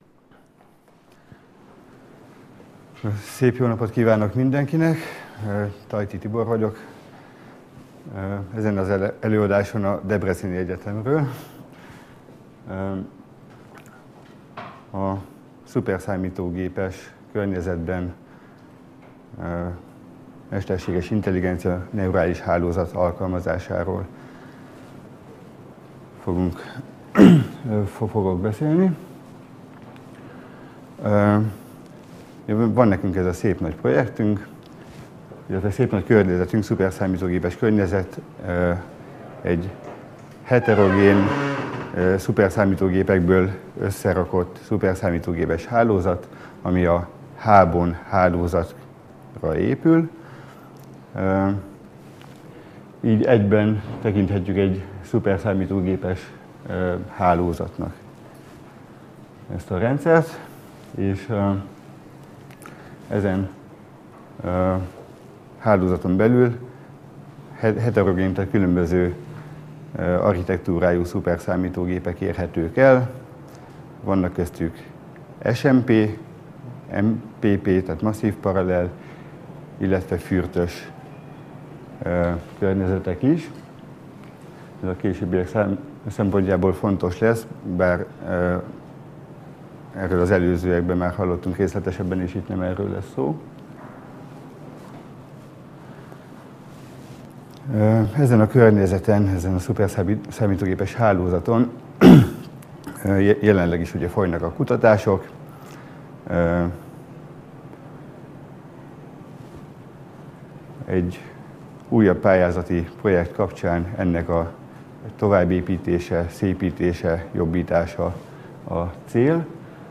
Networkshop 2013 konferencia